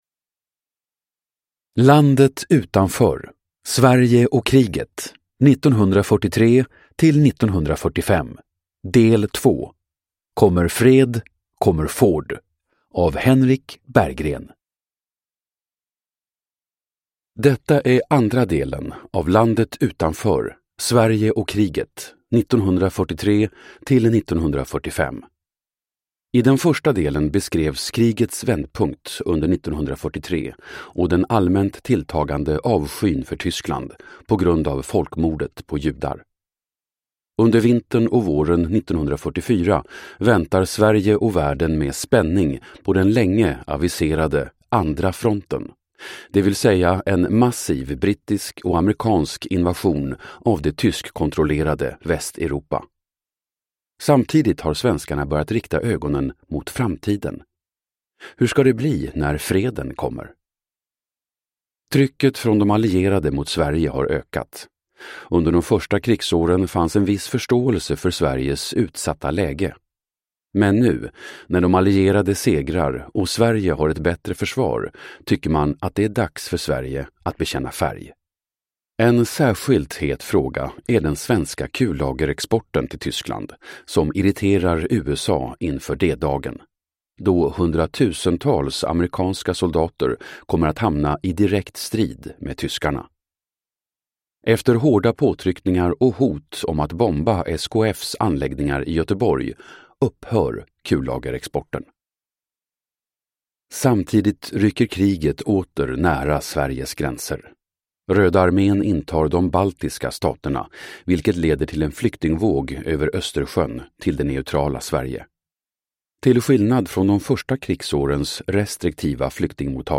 Landet utanför : Sverige och kriget 1943-1945. Del 3:2, Kommer fred, kommer Ford – Ljudbok